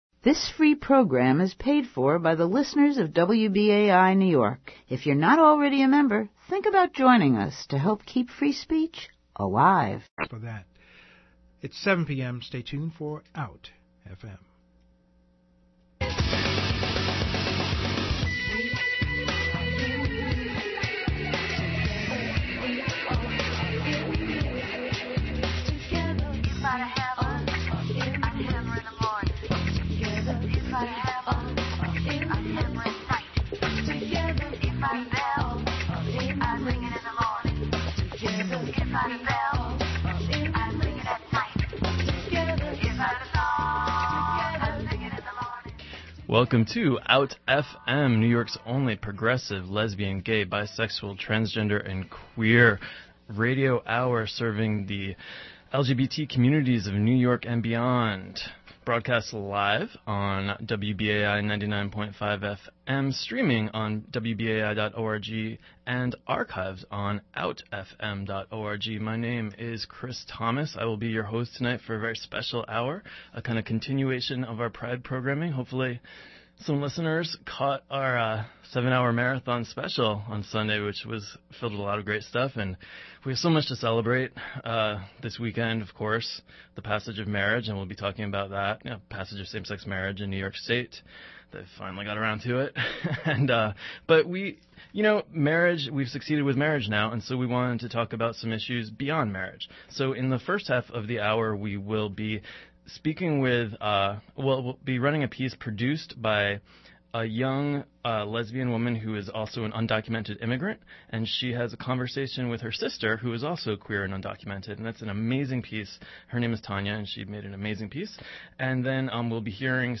Interview with Out-FM.
(Interview begins around 32 minute mark.)